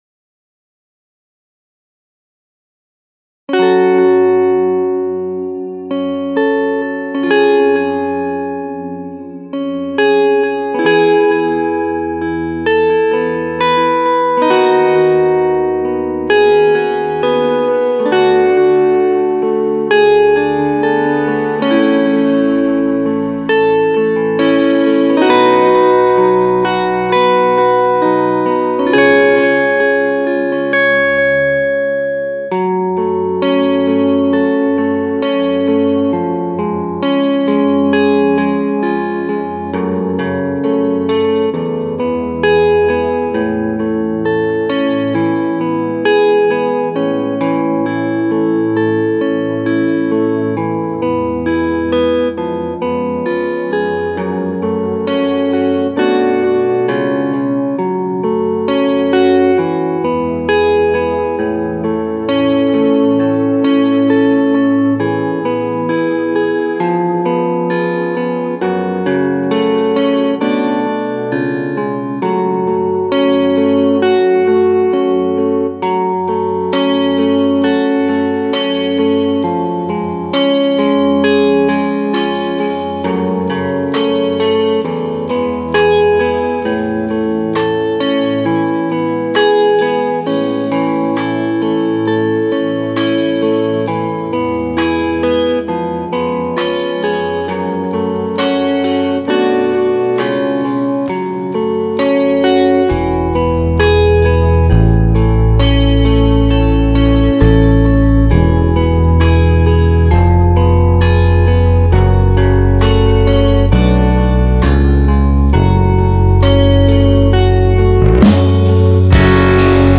minus-bez-bek-vokala-ojto-ne-vecher.mp3